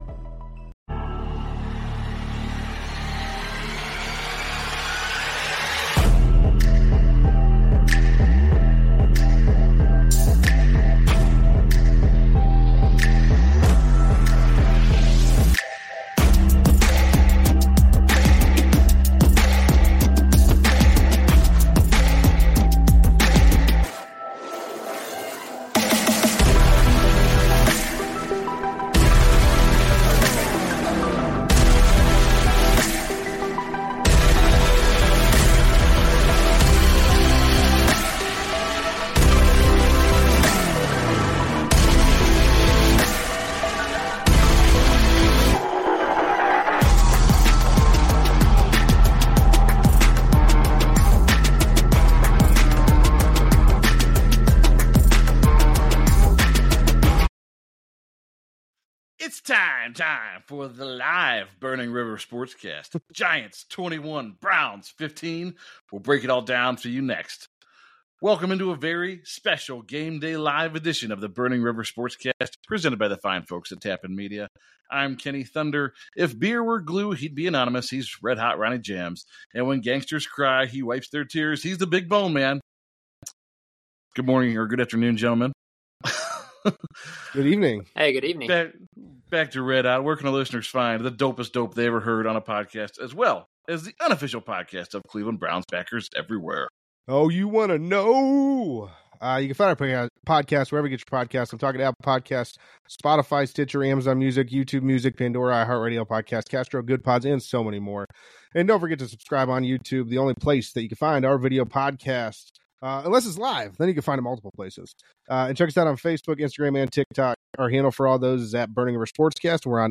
It's time! Time for Burning River Sportscast to go live again! Join as we discuss the Browns God awful week 3 showing in a loss to the lowly New York Giants.